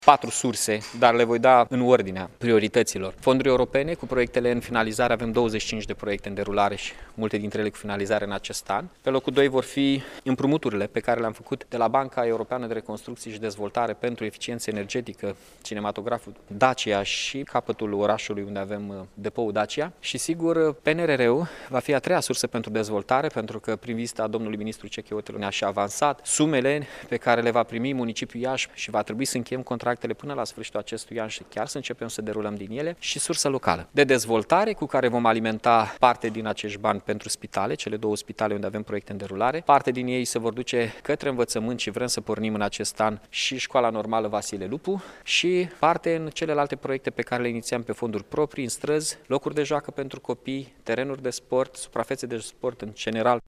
Primarul municipiului Iași, Mihai Chirica a informat, astăzi, într-o conferiță de presă că, din propunerile centralizate de la cetățeni, reiese că aceștia doresc amenajarea de locuri de parcare, modernizarea transportului public, fluidizarea traficului auto și amenajarea unor locuri de joacă pentru copii și pentru petrecerea timpului liber.